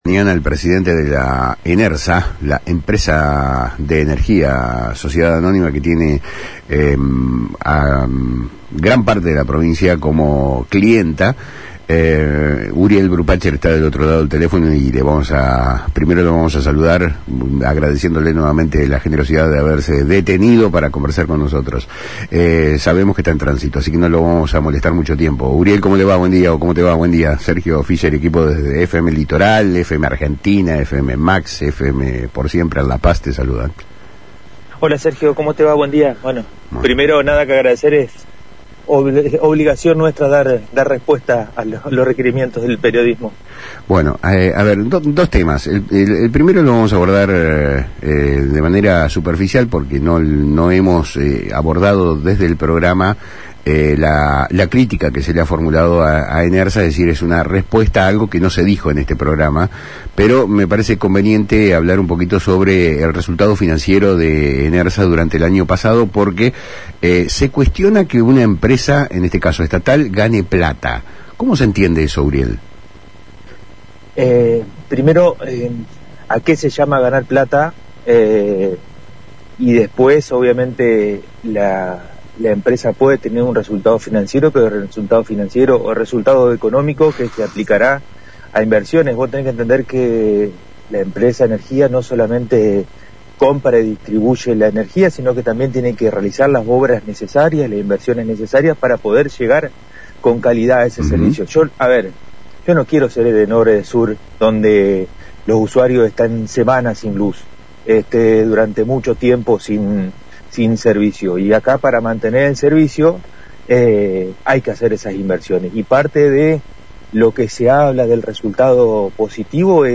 En una entrevista con Palabras Cruzadas por FM Litoral, Uriel Brupacher, presidente de ENERSA (Empresa de Energía de Entre Ríos Sociedad Anónima), habló sobre el desempeño financiero de la compañía, las inversiones recientes y la dirección estratégica.